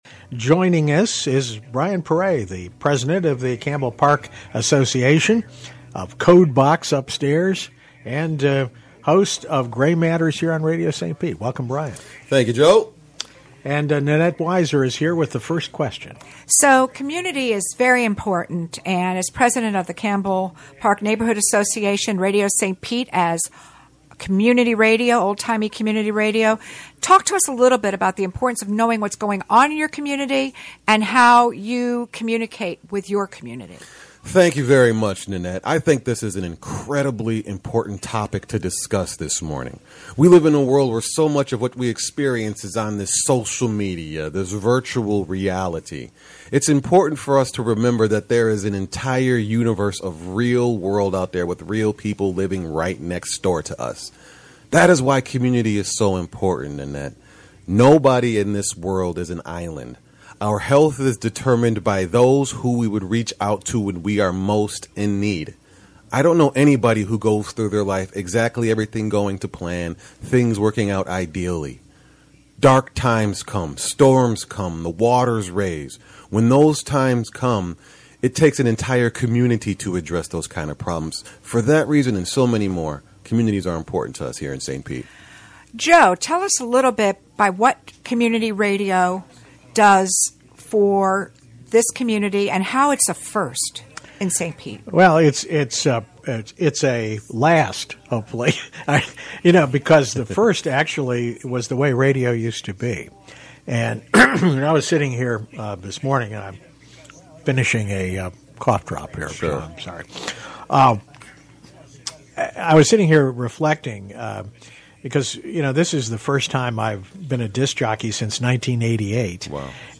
RadioStPete Day Interviews